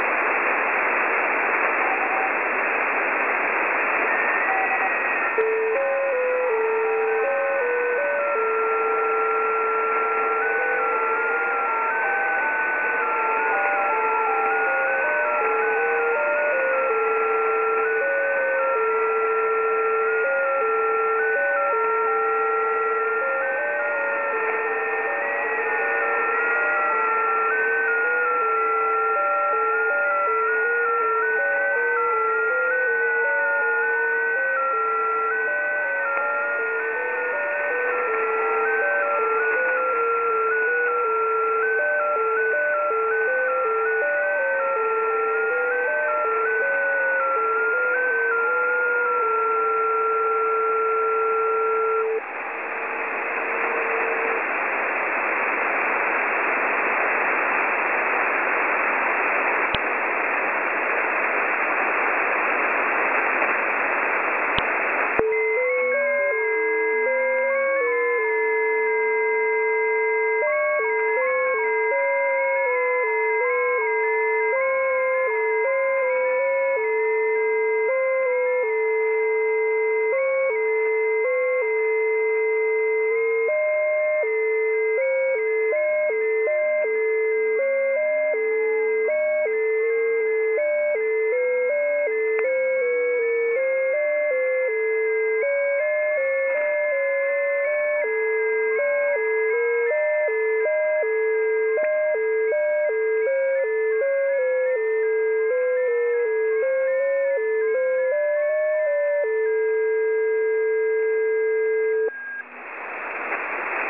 • ▲ ▼ Das habe ich eben beim WebSDR der Uni Twente auf 14076 kHz mitgeschnitten.
Wie SSTV klingt es eigentlich auch nicht, außerdem würde ich SSTV eher oberhalb 14200 kHz erwarten. Klingt auf jeden Fall sehr lustig.